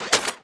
GUNMech_Reload_07_SFRMS_SCIWPNS.wav